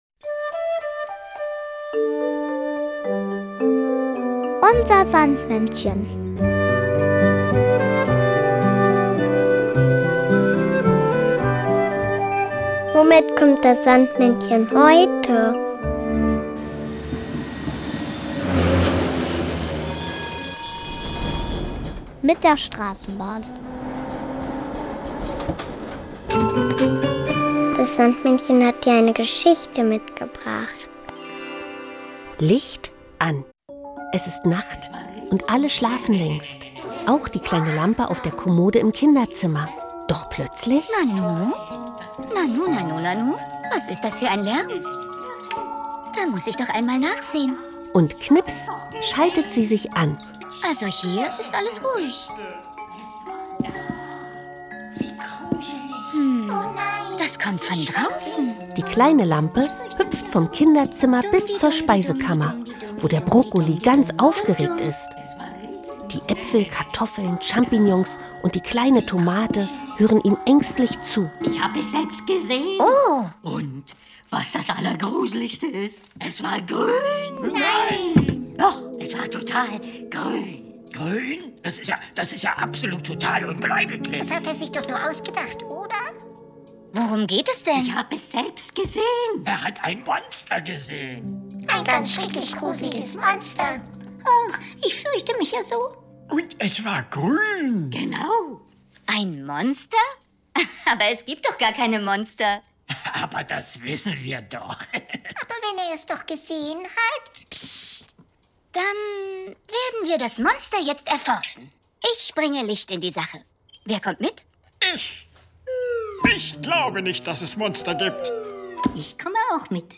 diese Geschichte mitgebracht, sondern auch noch das Kinderlied